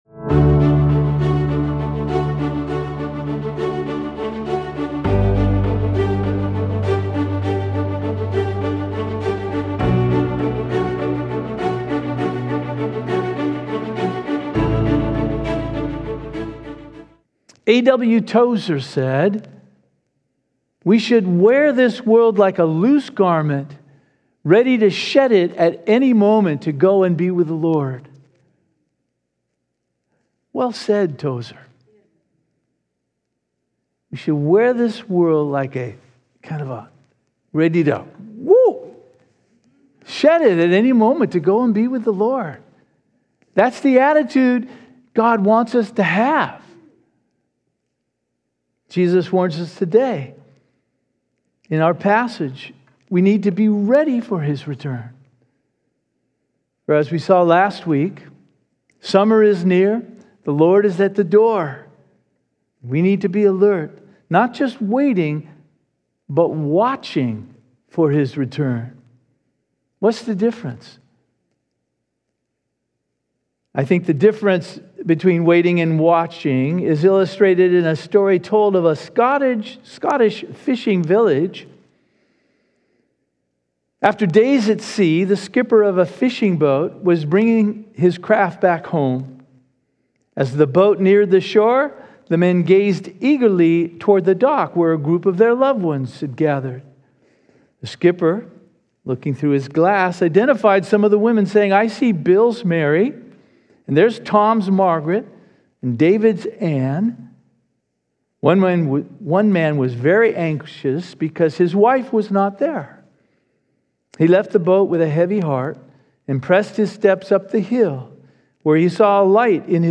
This is an audio podcast produced by Calvary Chapel Eastside in Bellevue, WA, featuring live recordings of weekly worship services.